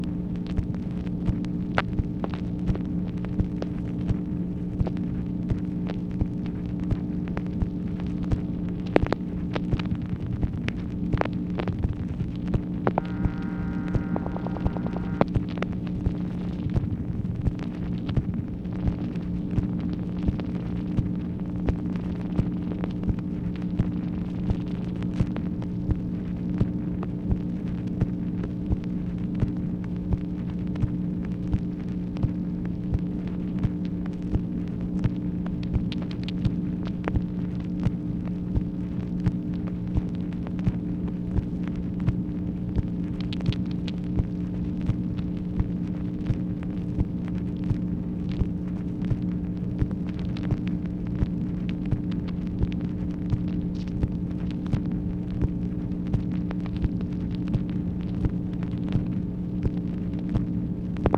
MACHINE NOISE, November 19, 1964
Secret White House Tapes | Lyndon B. Johnson Presidency